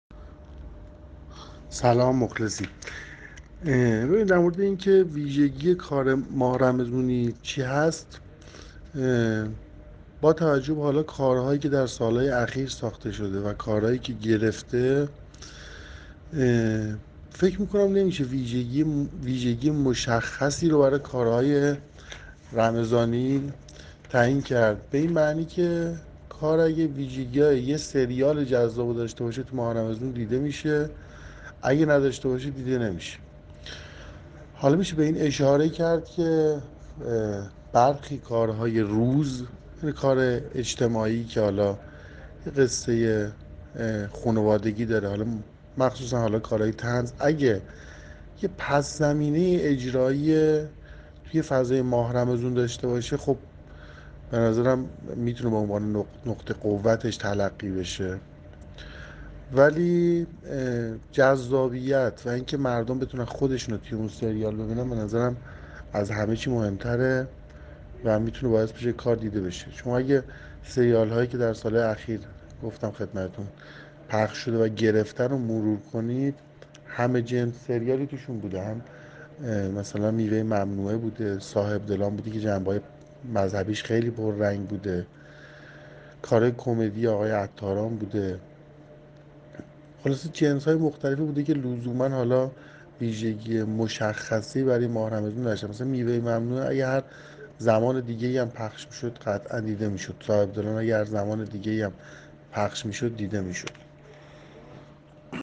یک فیلمنامه‌نویس گفت: صله‌ رحم در دو مناسبت نوروز و رمضان به شکلی جدی مورد نظر است که این اشتراکات می‌تواند الهام‌بخش تولیدات مناسبتی باشد.